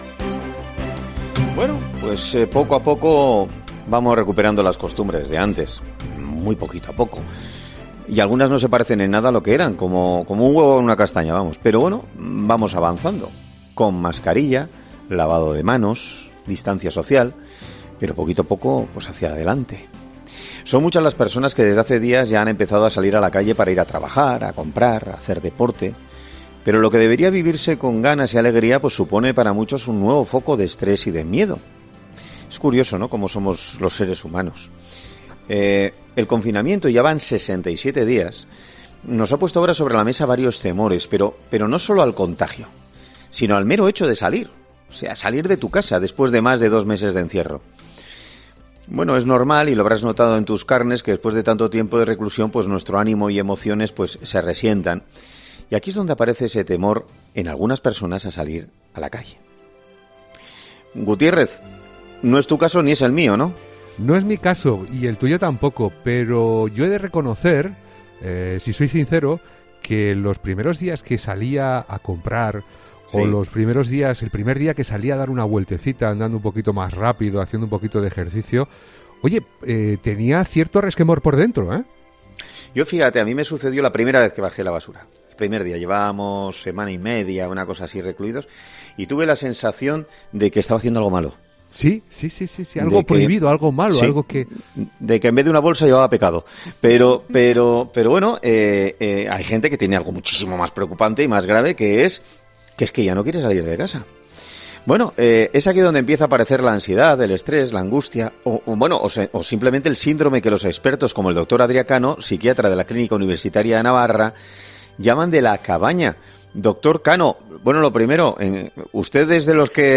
Presentado por Carlos Herrera, el comunicador más escuchado de la radio española, es un programa matinal que se emite en COPE, de lunes a viernes, de ...